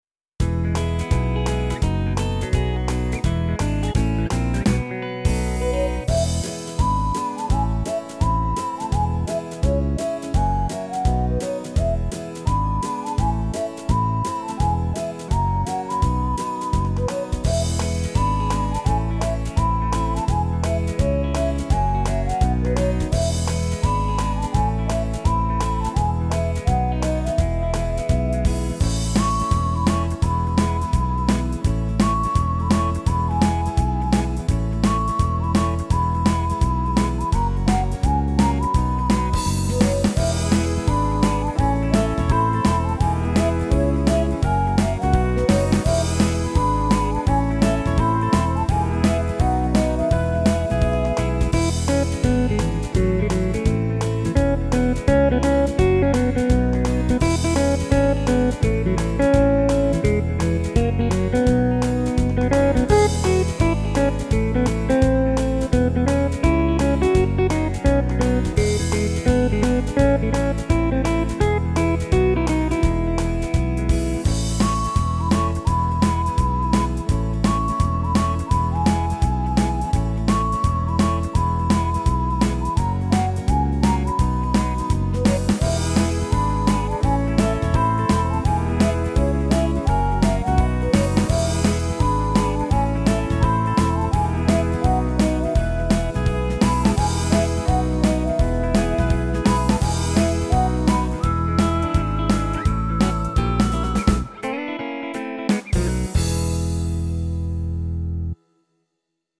[Country List]